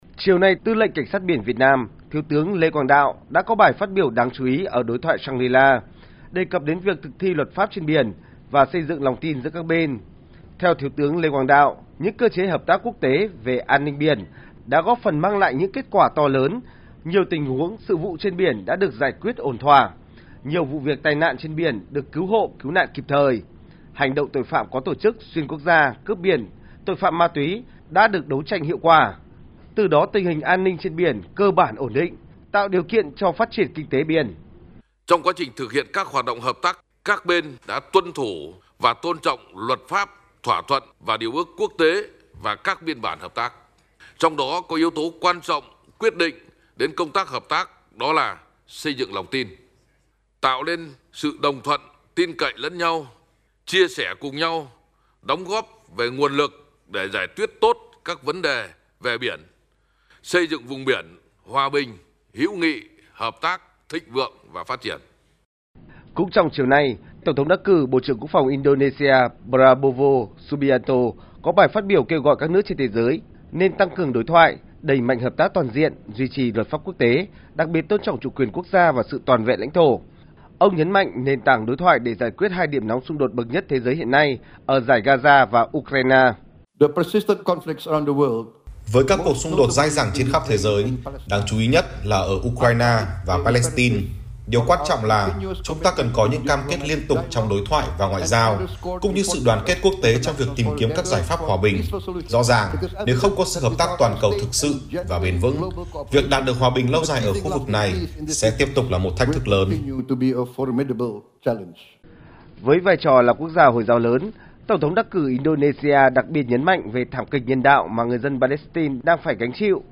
Ngày 1/6, tại Đối thoại Shang-ri La 2024, Tư lệnh Cảnh sát biển Việt Nam – Thiếu tướng Lê Quang Đạo có bài phát biểu đáng chú ý, đề cập đến việc thực thi pháp luật trên biển và xây dựng lòng tin giữa các Bên.